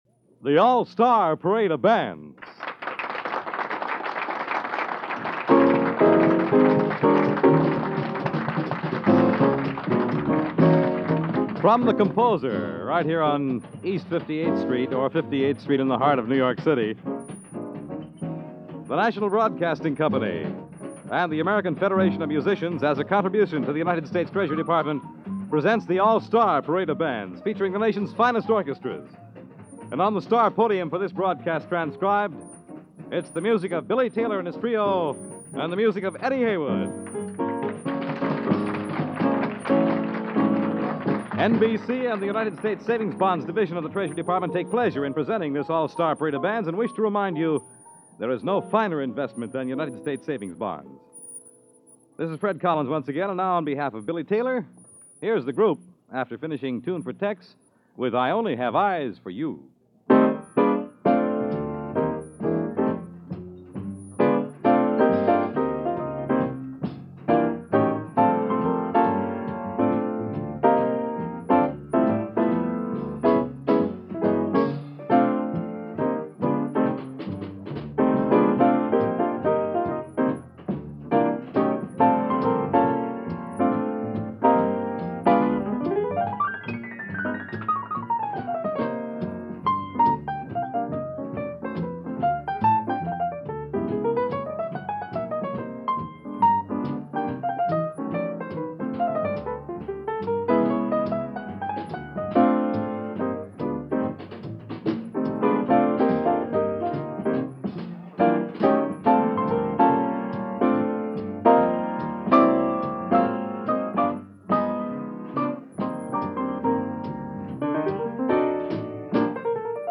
played several instruments before settling on Piano.